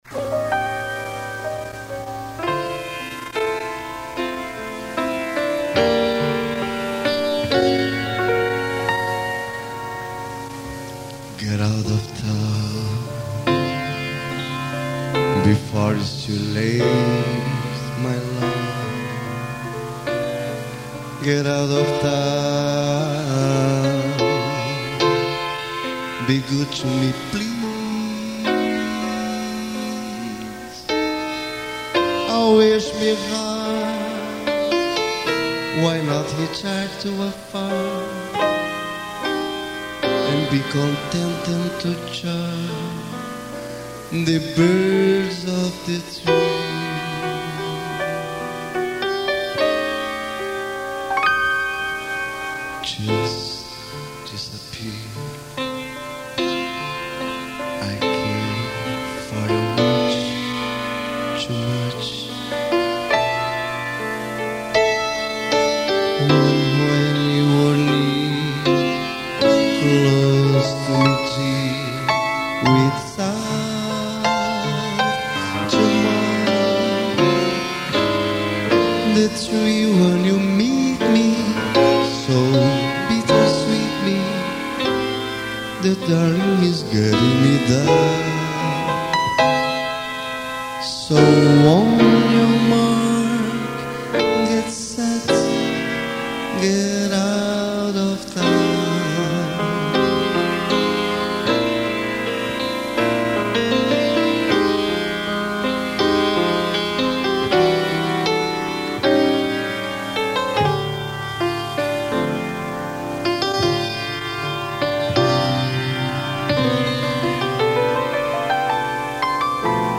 143   03:47:00   Faixa:     Jazz